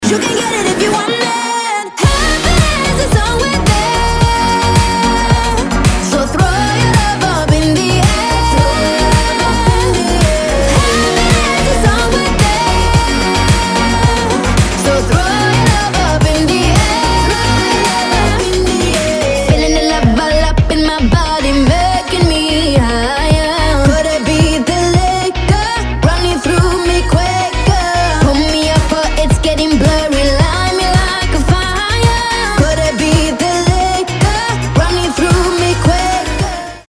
• Качество: 320, Stereo
поп
громкие
женский вокал
dance